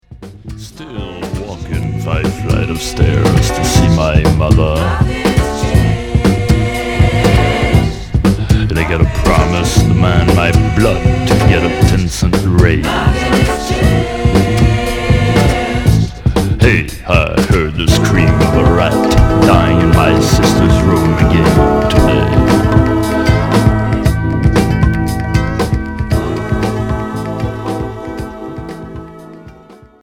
Groove pop